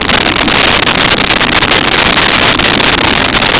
Sfx Surface Mud Loop
sfx_surface_mud_loop.wav